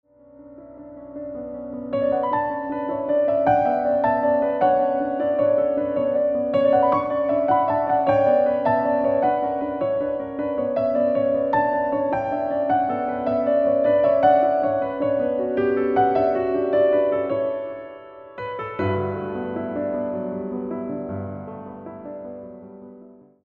including original jazz, new age and meditation music.